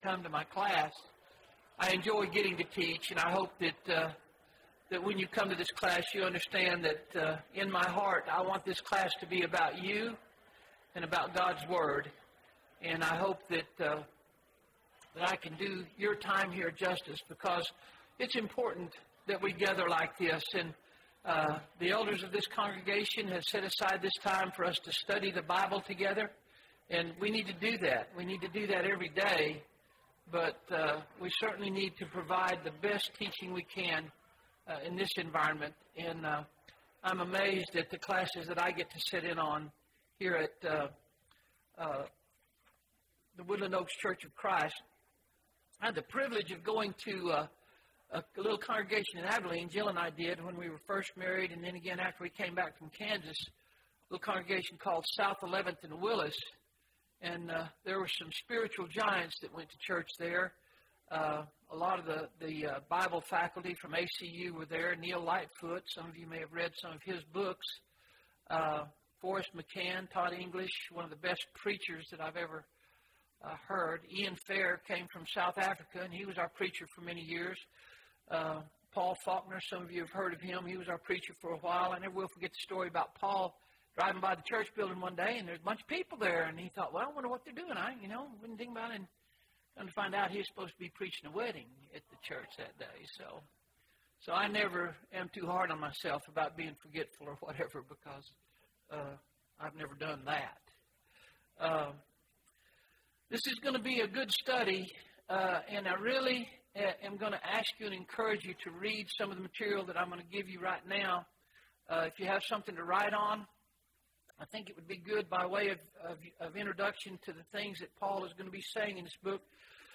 A Study of the Book of 1 Timothy (1 of 12) – Bible Lesson Recording
Sunday AM Bible Class